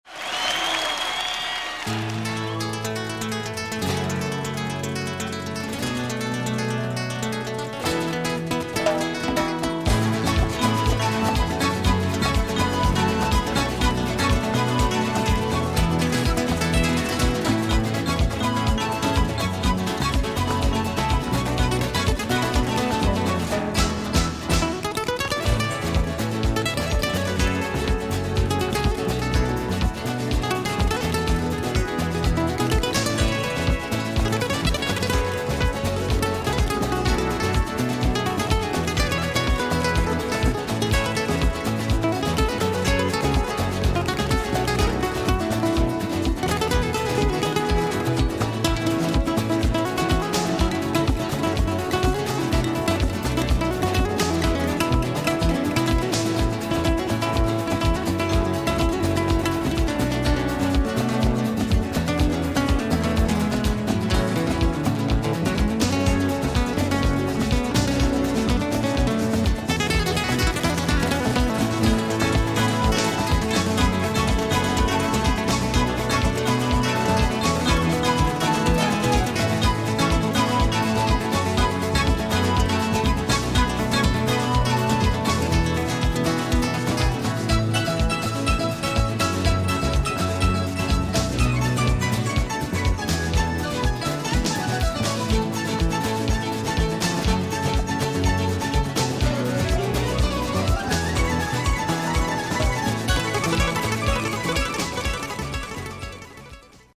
GKライブmp3 歌参考 2/G inst.